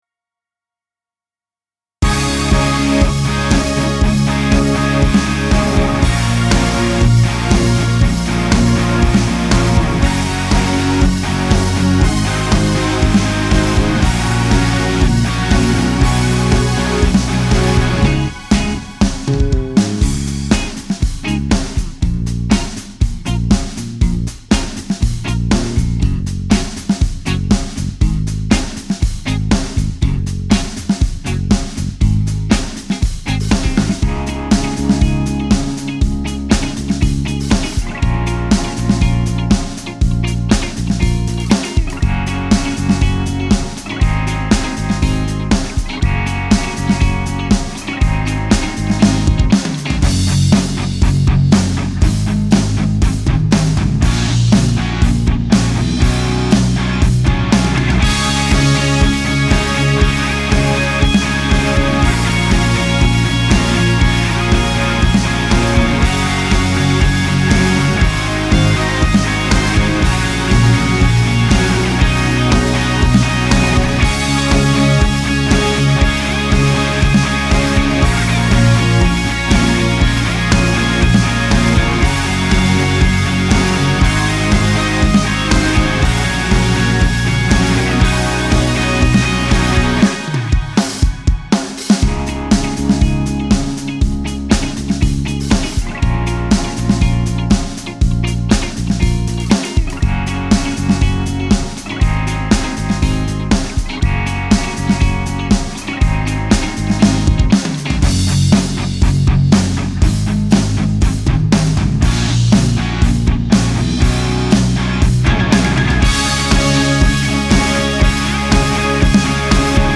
Karaoke alap:
Sulidal_alap.mp3